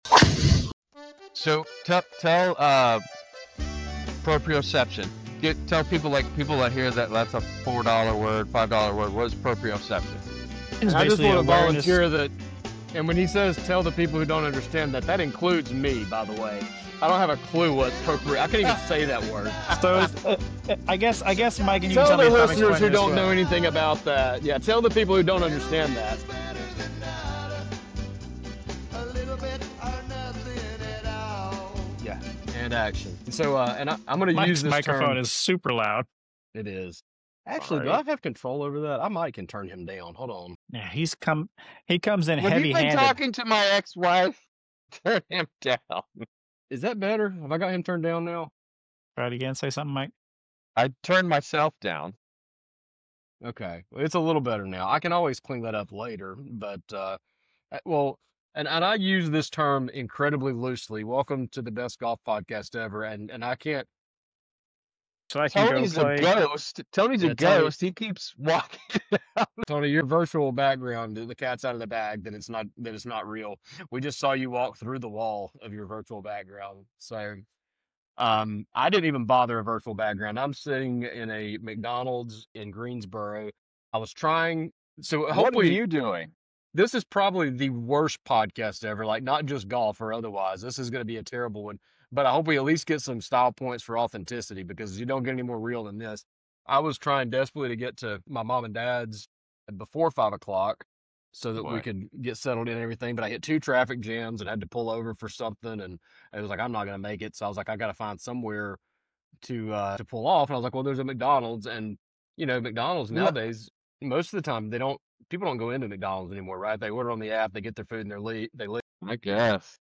Defining Proprioceptive from the McDonalds Parking Lot